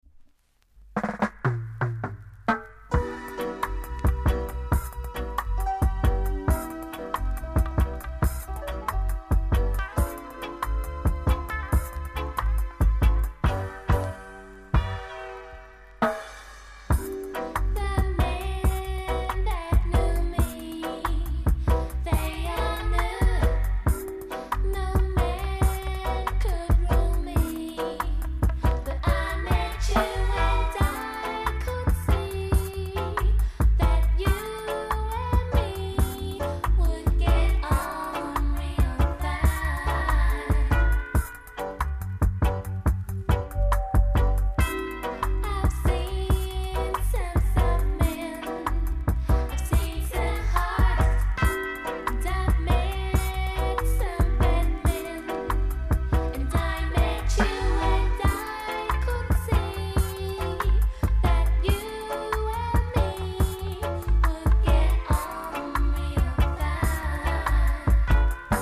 ※小さなチリノイズが少しあります。
コメント CUTEな歌声のNICE UK FEMALE LOVERS!!